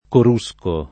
corruscare v.; corrusco [korr2Sko], -schi — latinismo dell’uso lett. per «balenare, scintillare» — meno com. coruscare: corusco [